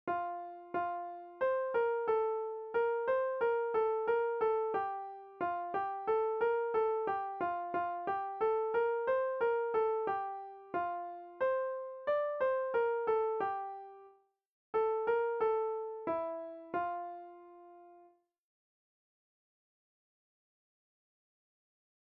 Nahrávky živých muzikantů si můžete poslechnout u písniček Vločka a Jinovatka, u ostatních si můžete poslechnout zatím jen melodie generované počítačem 🙂